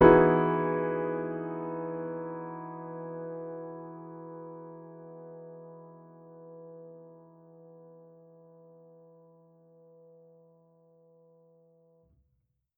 Index of /musicradar/jazz-keys-samples/Chord Hits/Acoustic Piano 1
JK_AcPiano1_Chord-Emaj9.wav